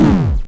undertale_hit.wav